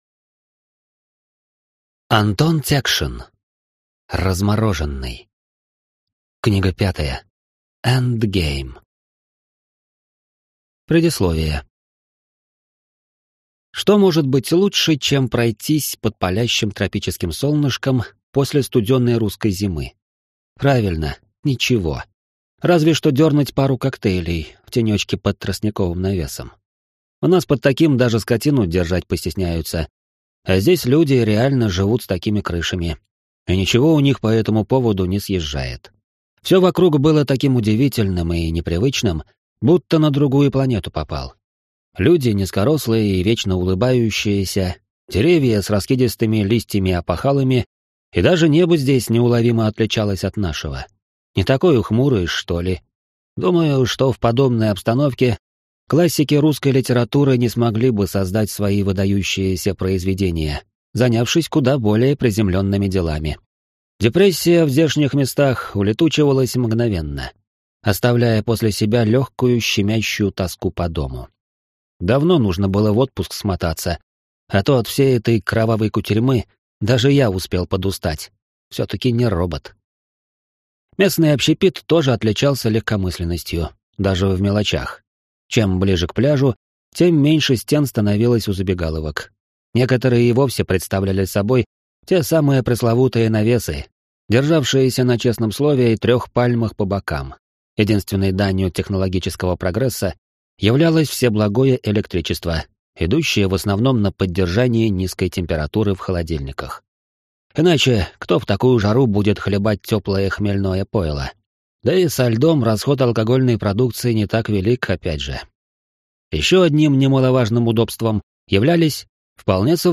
Аудиокнига Размороженный. Книга 5. EndGame | Библиотека аудиокниг